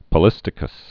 (pə-lĭstĭ-kəs)